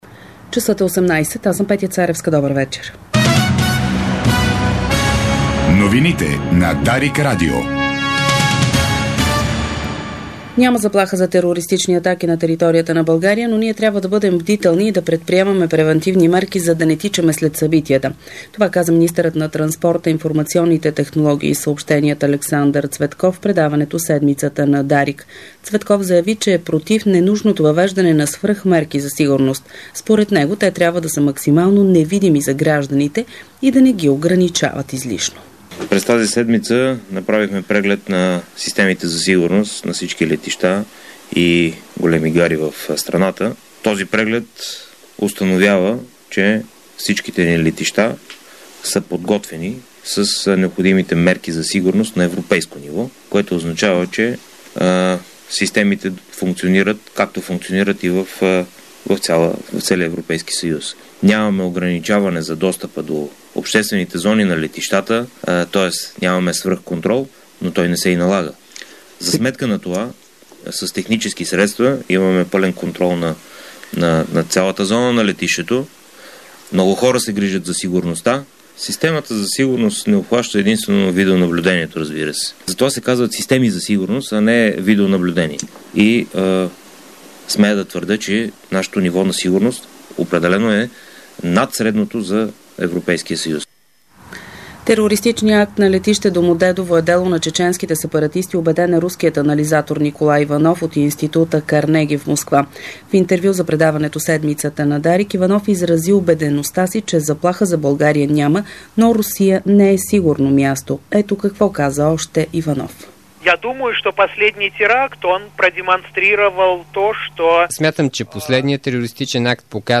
Обзорна информационна емисия - 29.01.2011